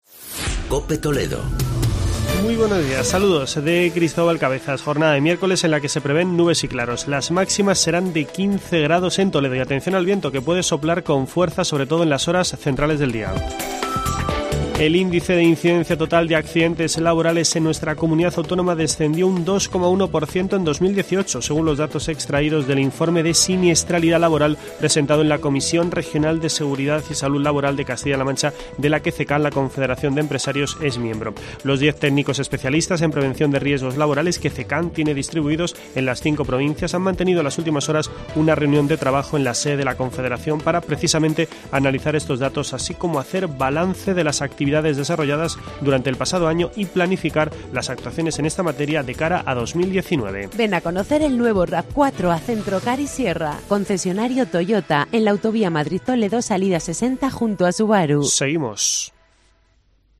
Boletín informativo de la Cadena COPE en la provincia de Toledo.